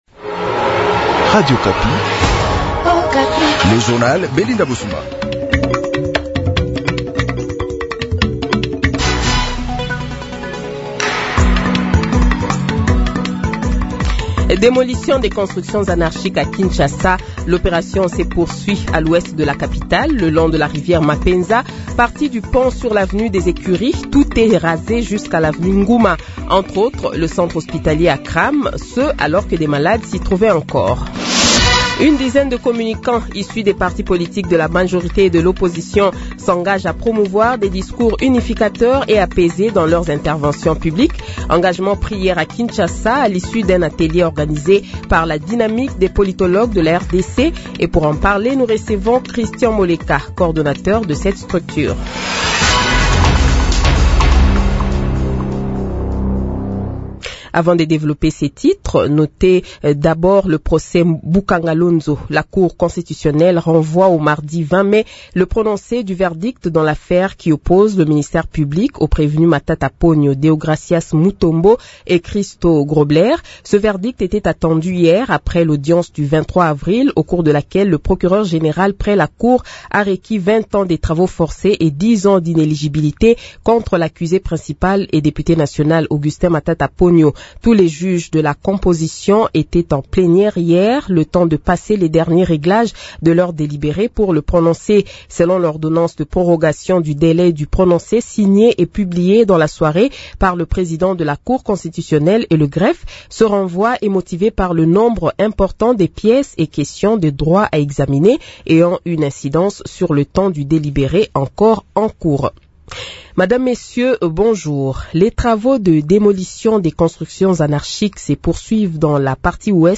Le Journal de 7h, 15 Mai 2025 :